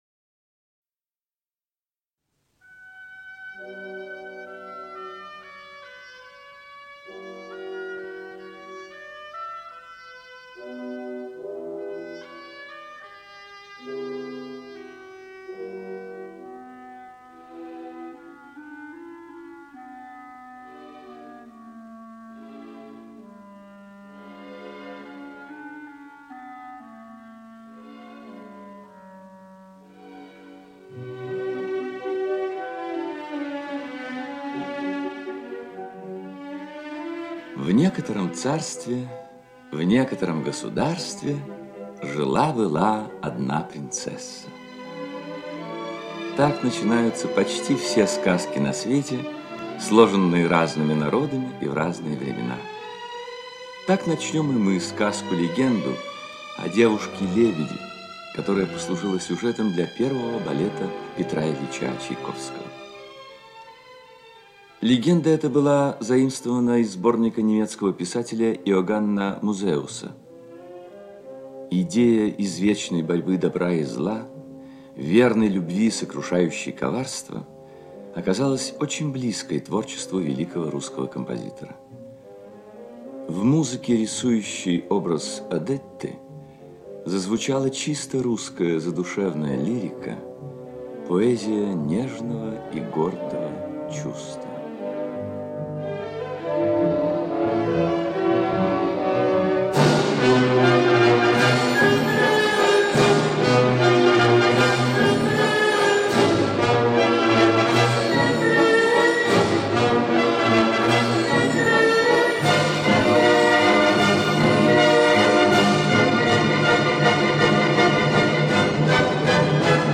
Лебединое озеро - музыкальная сказка Чайковского - слушать
Лебединое озеро - музыкальная сказка Чайковского П.И. Классическая музыка величайшего композитора для взрослых и детей.